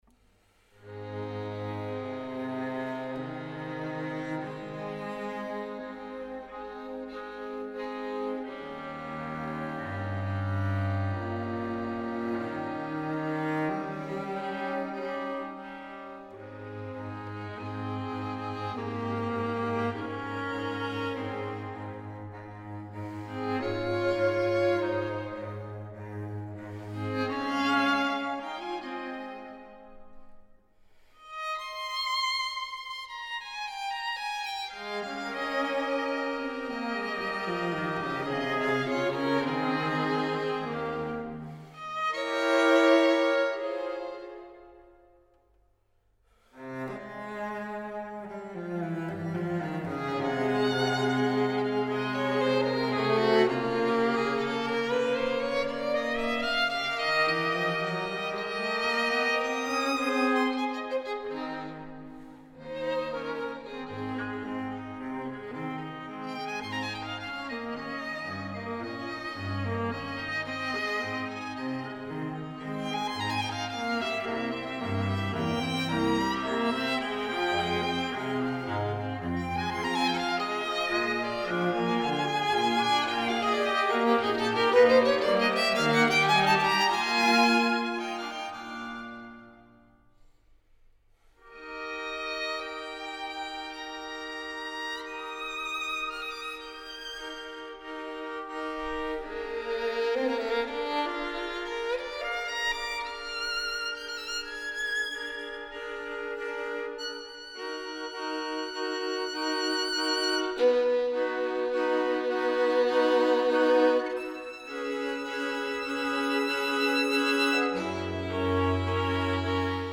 Adagio